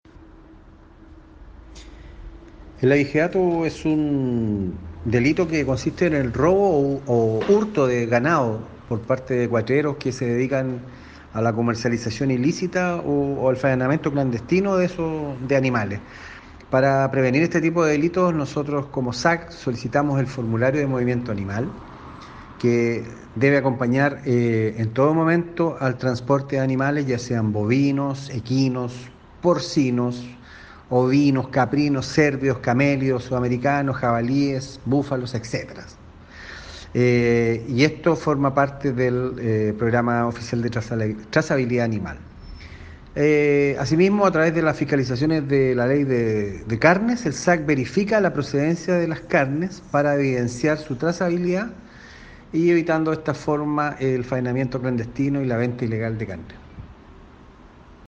Por su parte, el Director Regional (s) del SAG, Jorge Fernández, indicó que